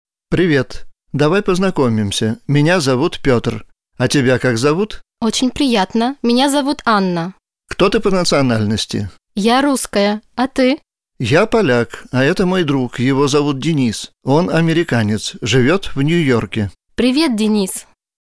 Narodowości - dialog z mp3
Materiał pochodzi z audio kursu Rosyjski Kurs podstawowy
rosyjski_dialog1.mp3